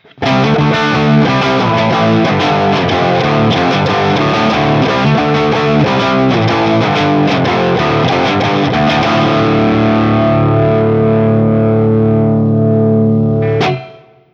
This 1994 Guild S100 can sound chimy, articulate, raunchy, and everything in-between.
As usual, for these recordings I used my Axe-FX II XL+ setup through the QSC K12 speaker recorded direct via USB to my Macbook Pro.